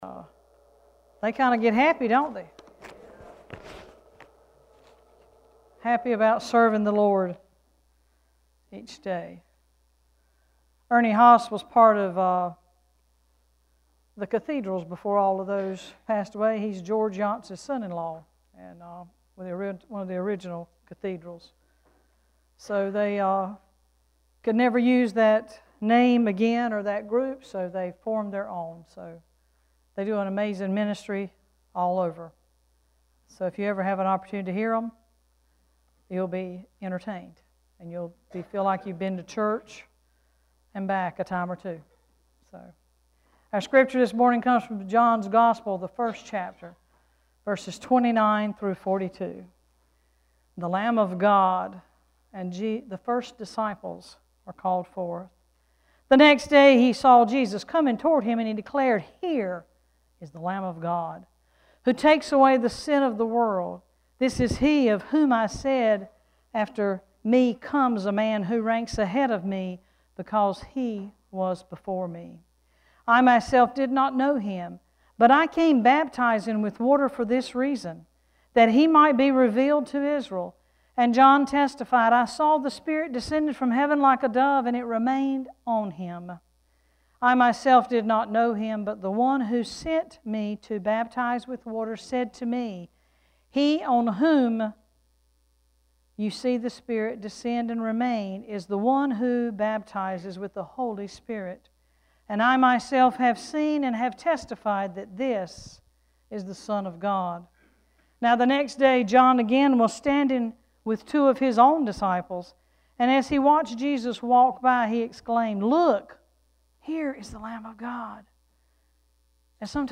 Worship Service 1-15-17: “The Breath of Passion”